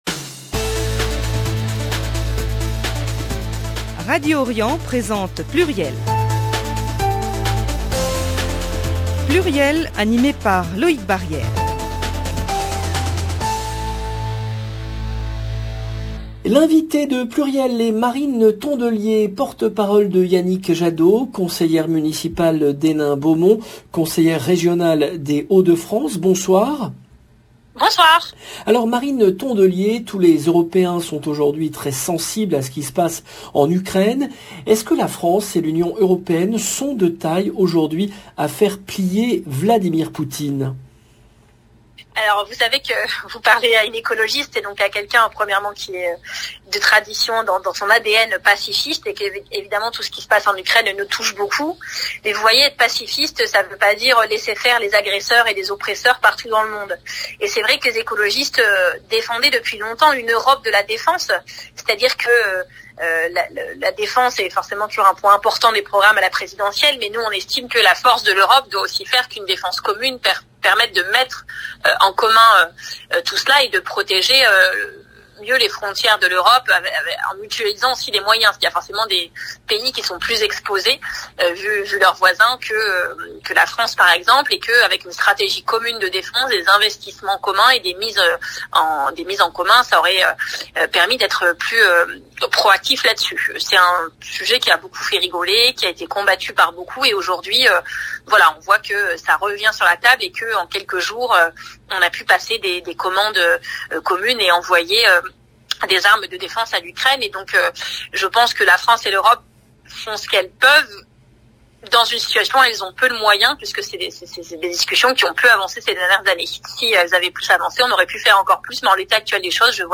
L’invitée de PLURIEL est Marine Tondelier, porte-parole de Yannick Jadot, conseillère municipale d’Hénin Beaumont, conseillère régionale des Hauts-de-France